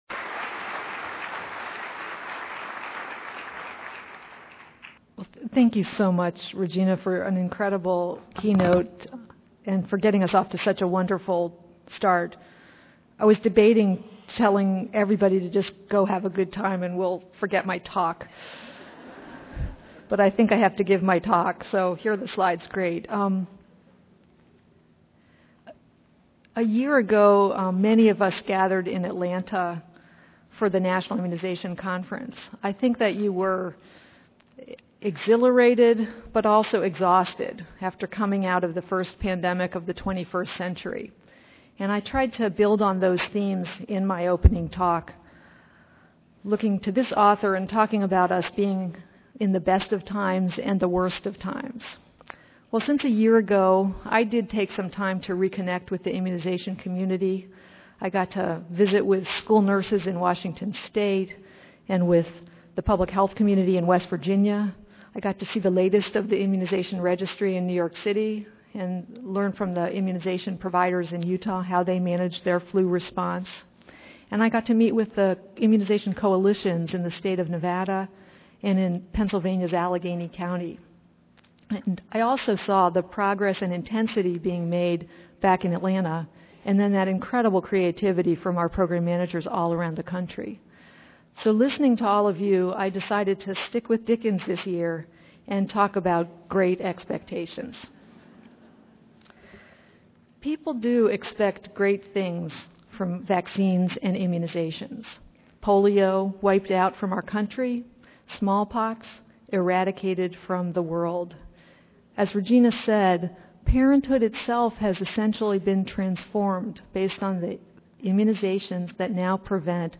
Keynote Address